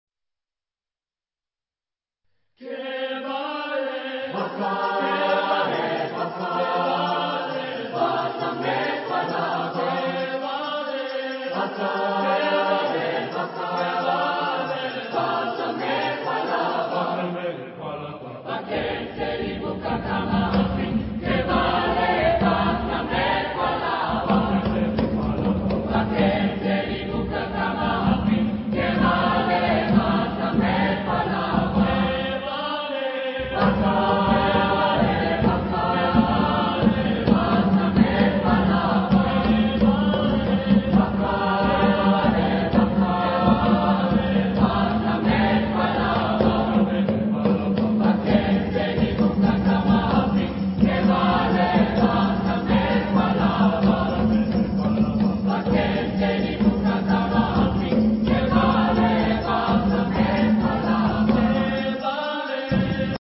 Genre-Stil-Form: traditionell
Charakter des Stückes: con brio ; unbeschwert
Chorgattung: SATB  (4 gemischter Chor Stimmen )
Tonart(en): F-Dur